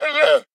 Minecraft Version Minecraft Version snapshot Latest Release | Latest Snapshot snapshot / assets / minecraft / sounds / mob / llama / idle1.ogg Compare With Compare With Latest Release | Latest Snapshot